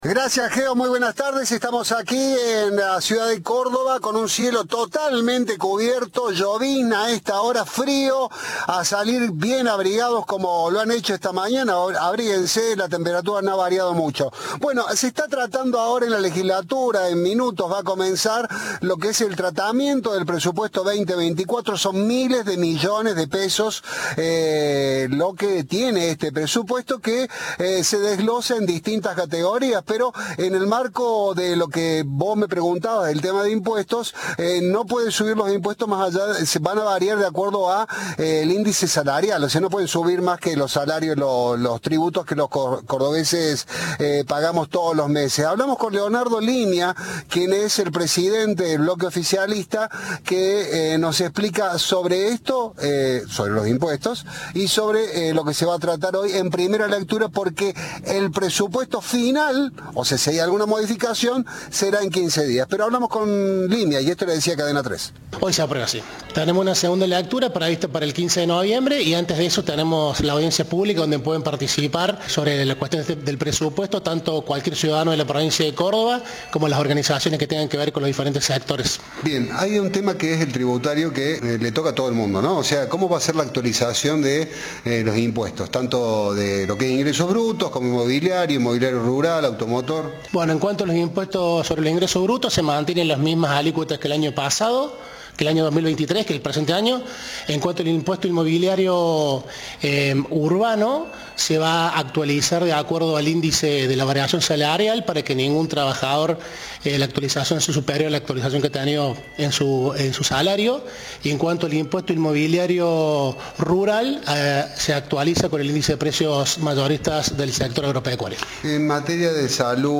Leonardo Limia, titular del bloque oficialista del PJ, dijo a Cadena 3 que más de 550.000 millones de pesos estarán destinados a infraestructura para toda la provincia.
Informe